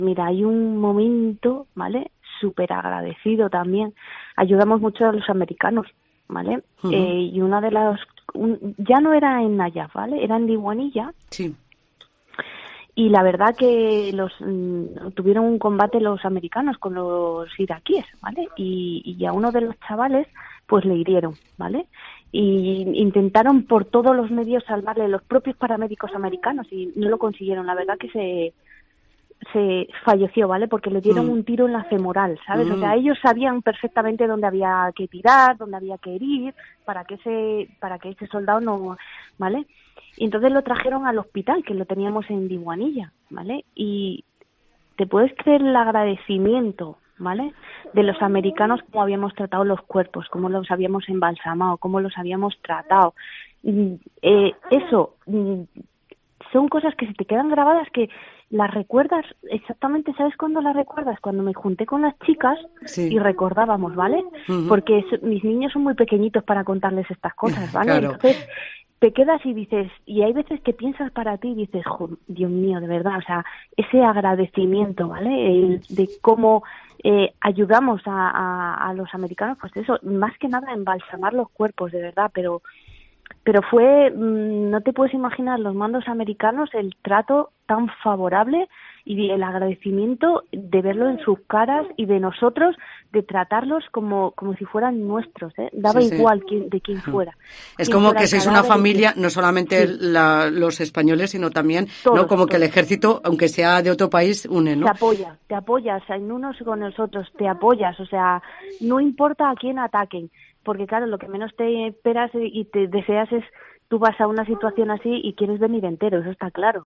"y porque es mi cumpleaños" me cuenta con una amplia sonrisa que se le intuye a través del teléfono por el se cuela